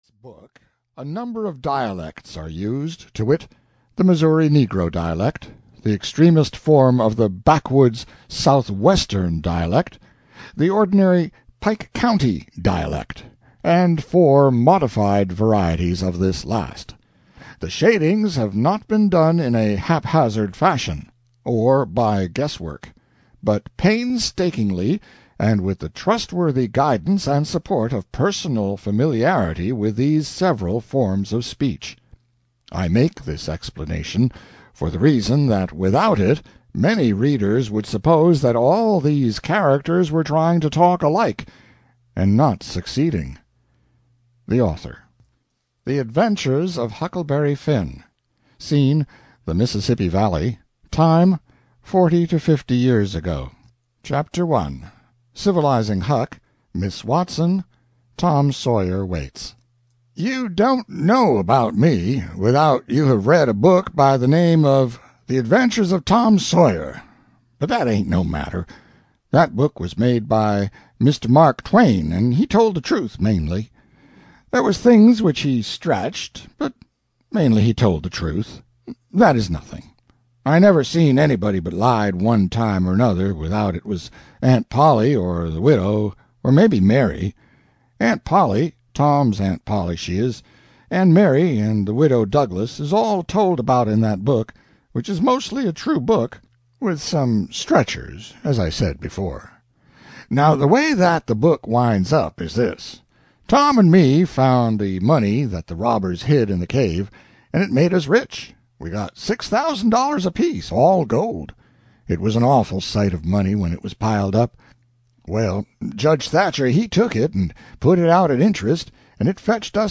Audio knihaThe Adventures of Huckleberry Finn (EN)
Ukázka z knihy